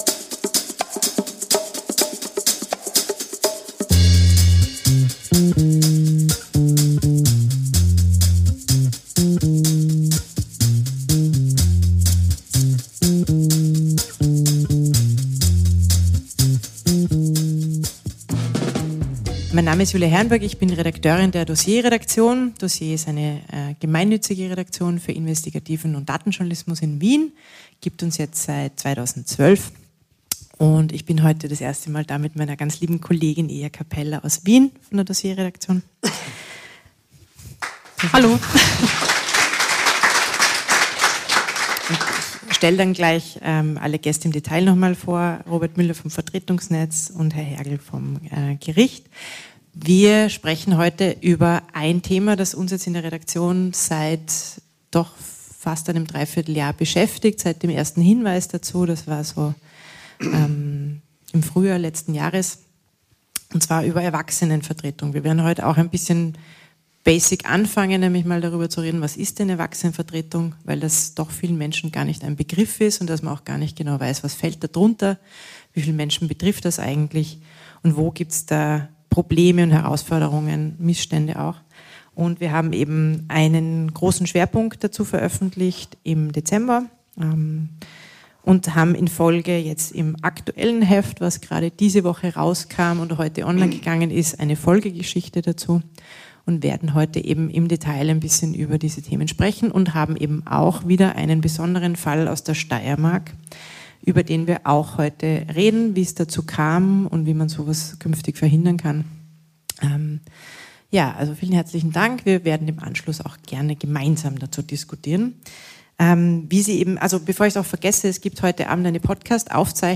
DOSSIER auf der Bühne des Grazer Theater im Bahnhof